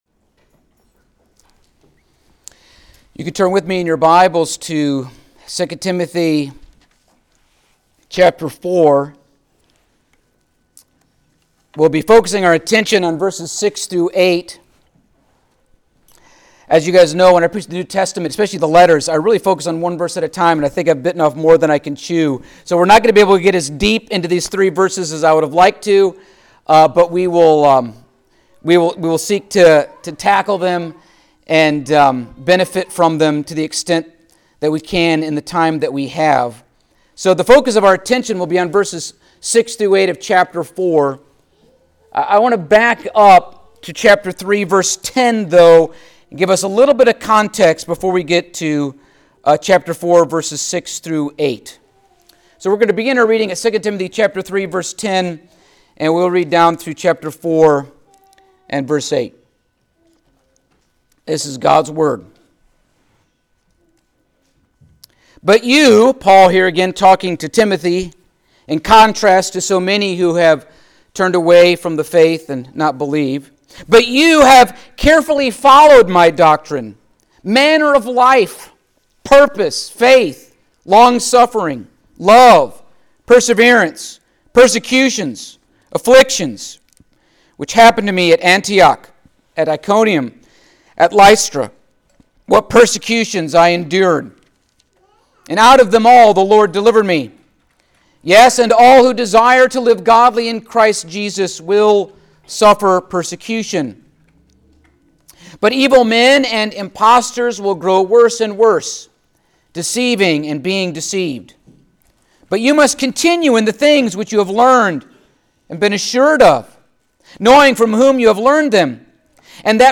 New Years Sermon
Service Type: Sunday Morning